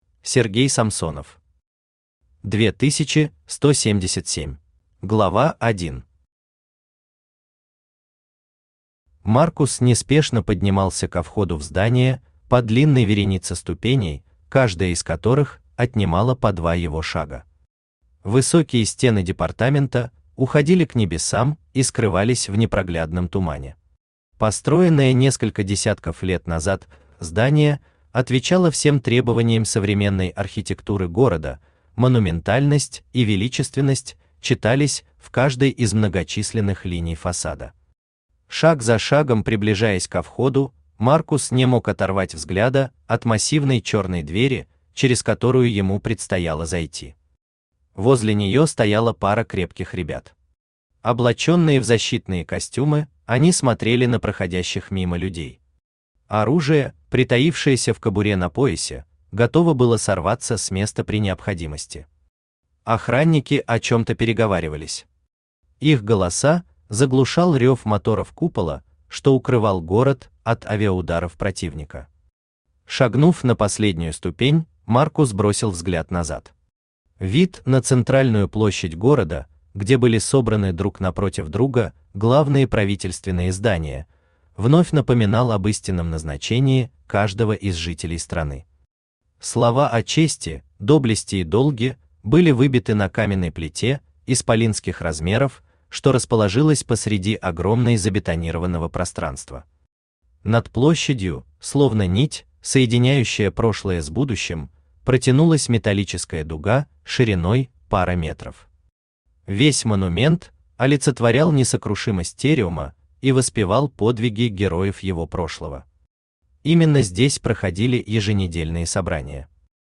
Аудиокнига 2177 | Библиотека аудиокниг
Aудиокнига 2177 Автор Сергей Самсонов Читает аудиокнигу Авточтец ЛитРес.